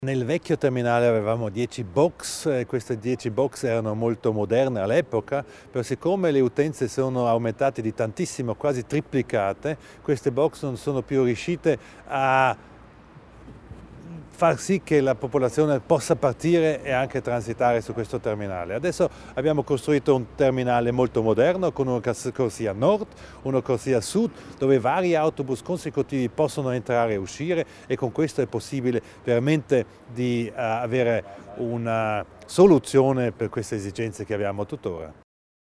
L'Assessore Widmann illustra le principali novità dell'autostazione di Bressanone